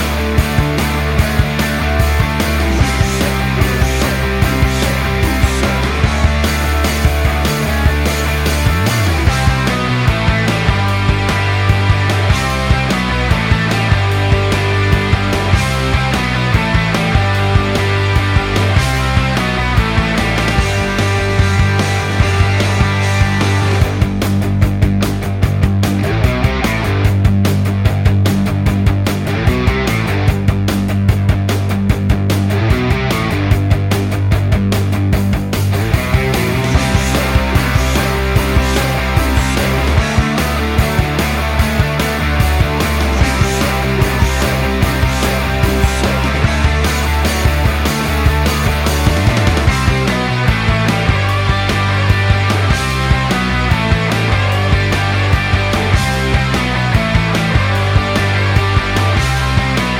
no Backing Vocals Punk 3:20 Buy £1.50